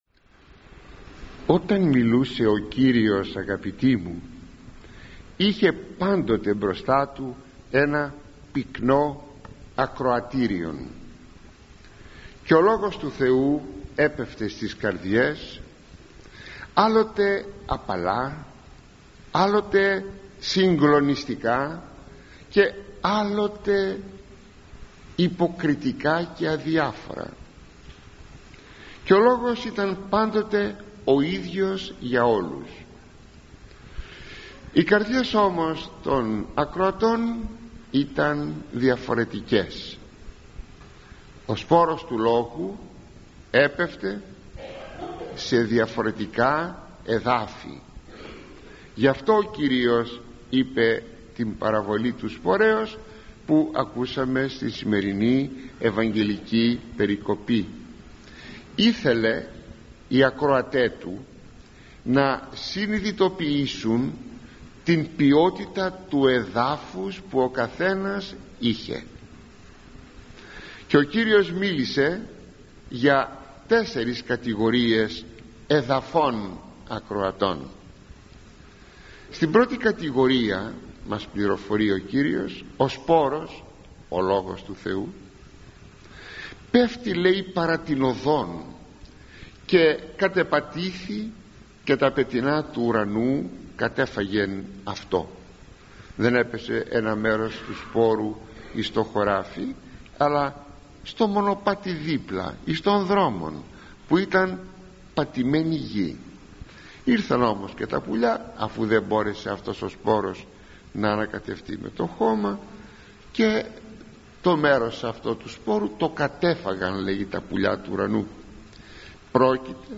omiliai_kyriakvn_651.mp3